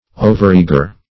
Overeager \O`ver*ea"ger\, a.